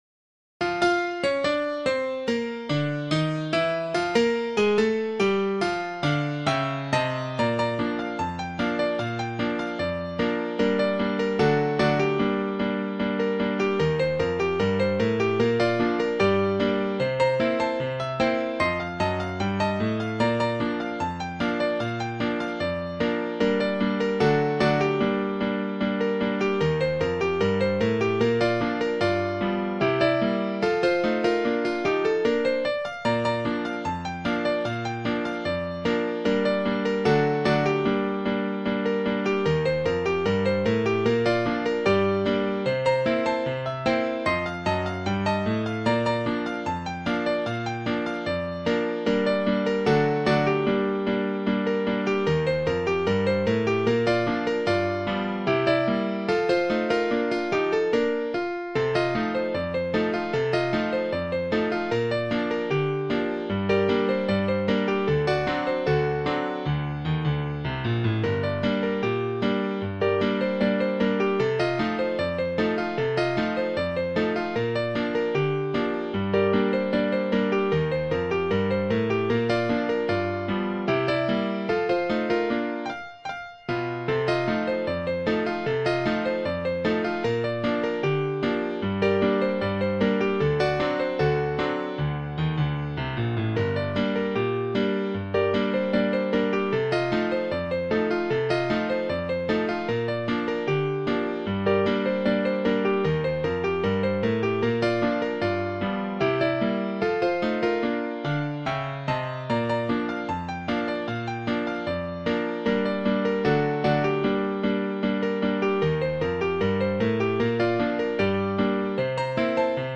Ragtime Sheet Music
a piano rag